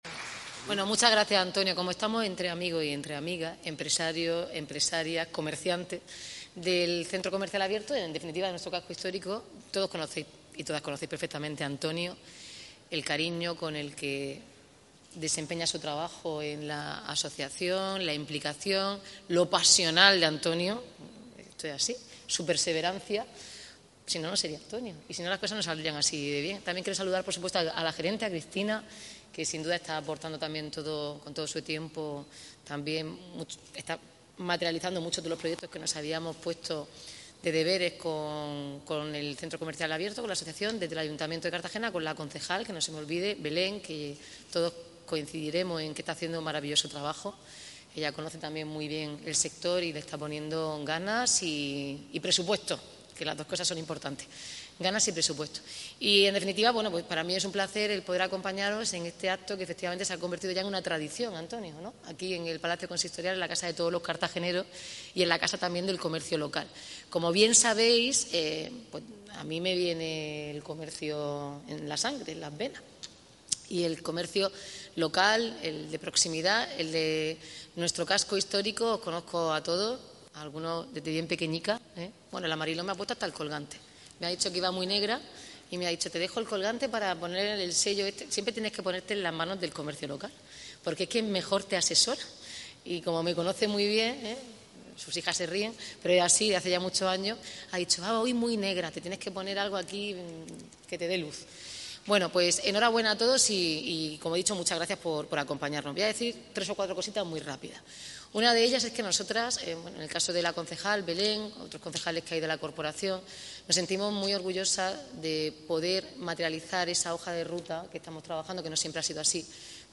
Declaraciones
ha presidido este martes 9 de diciembre el acto de entrega de premios que se ha celebrado en en el Vestíbulo del Palacio Consistorial.